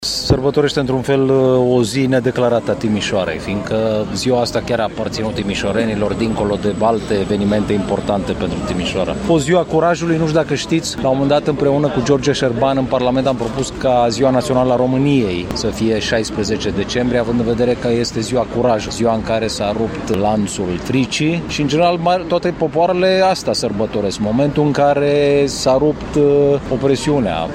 Printre participanții la eveniment s-a numărat și subprefectul Ovidiu Drăgănescu.
02-Ovidiu-Draganescu-21.mp3